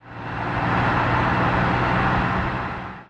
CosmicRageSounds / ogg / general / highway / oldcar / tovertake3.ogg